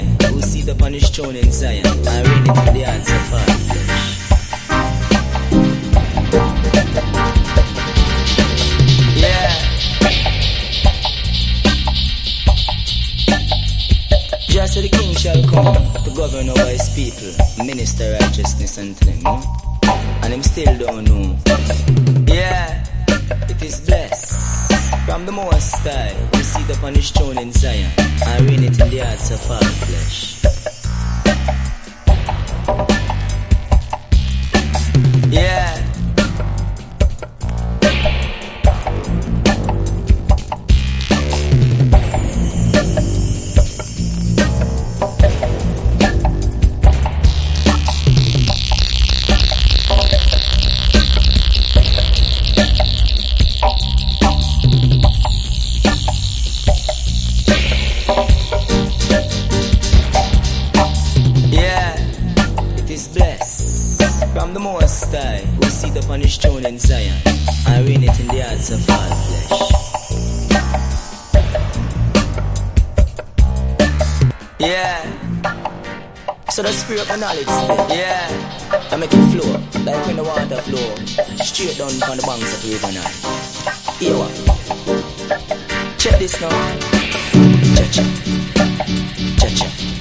• REGGAE-SKA
DUB / UK DUB / NEW ROOTS